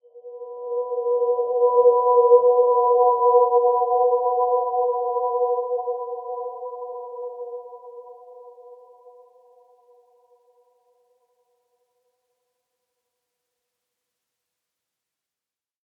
Dreamy-Fifths-B4-f.wav